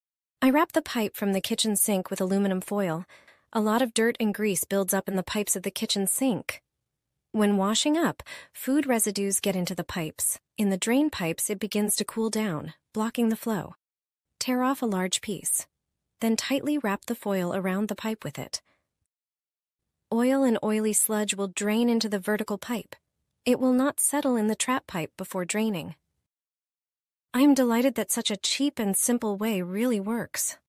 Wrap the pipes under sink sound effects free download